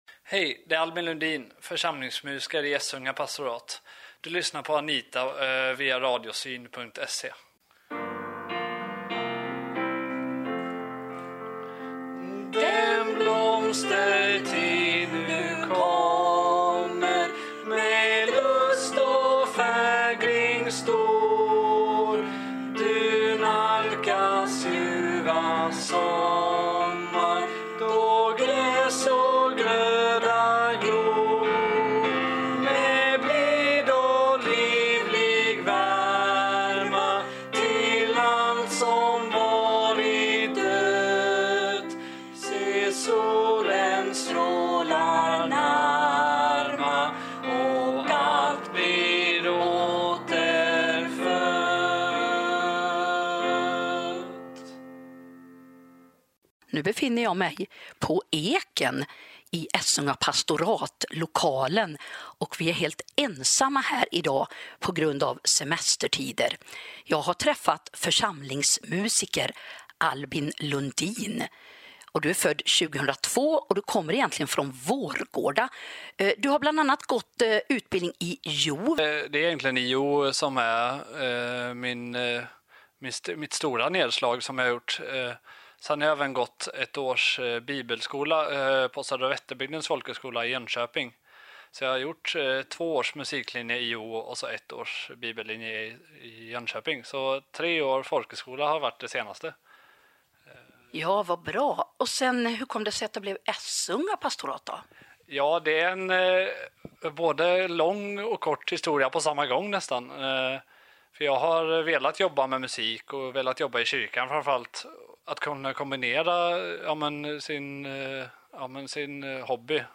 Vid pianot, vi sjunger.
Spännande intervju. Det var en ung kyrkomusiker.